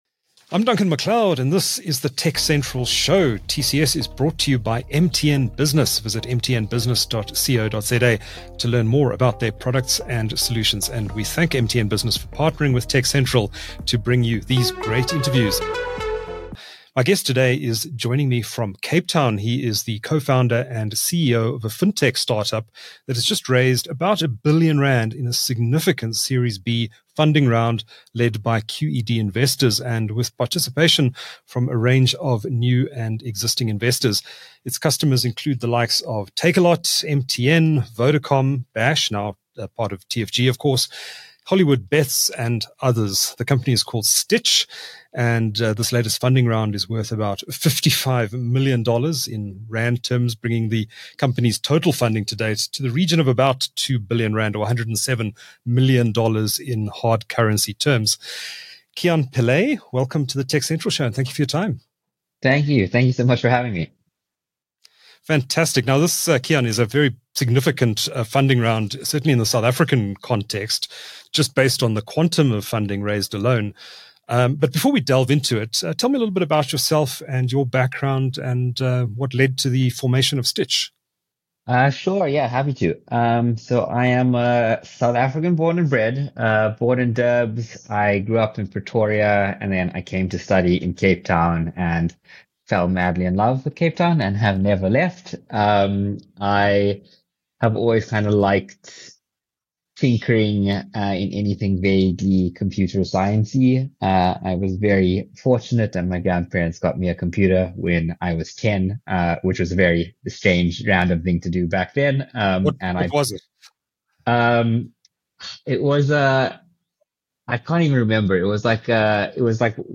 The TechCentral Show (TCS, for short) is a tech show produced by South Africa's leading technology news platform. It features interviews with newsmakers, ICT industry leaders and other interesting people.